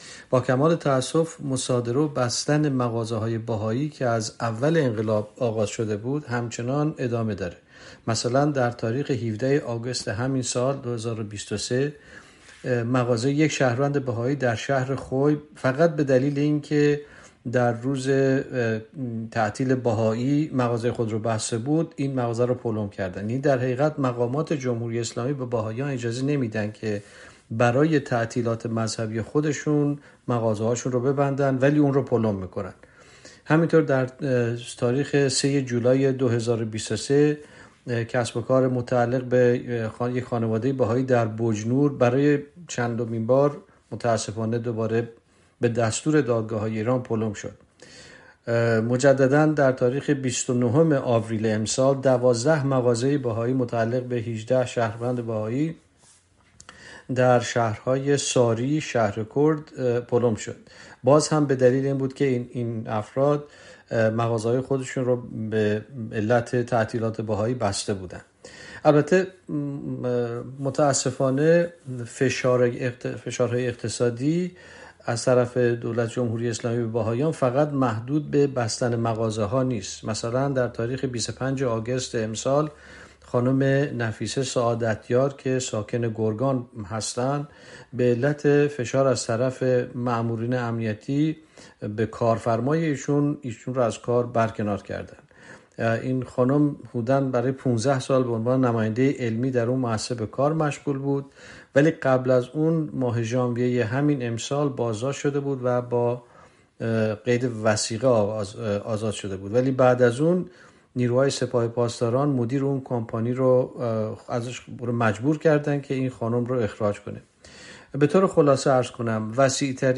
مصاحبه های اختصاصی